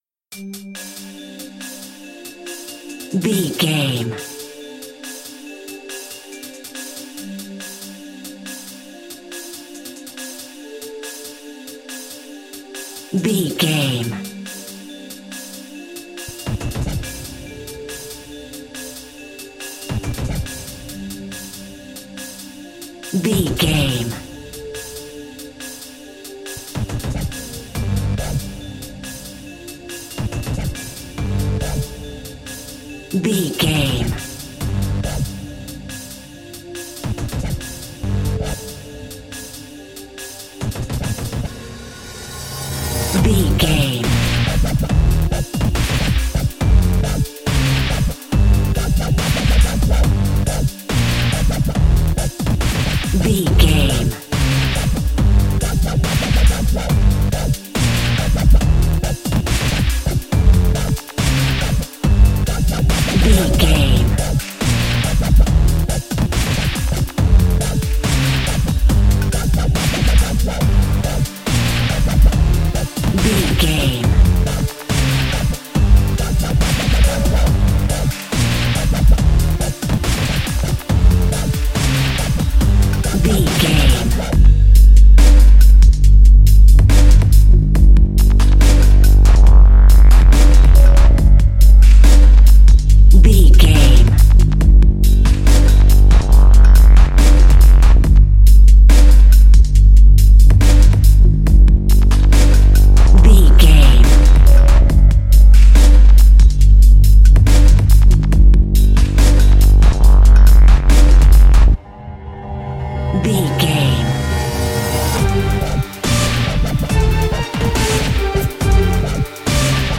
Aeolian/Minor
drum machine
synthesiser
orchestral
orchestral hybrid
dubstep
aggressive
energetic
intense
strings
drums
bass
synth effects
wobbles
drum beat
epic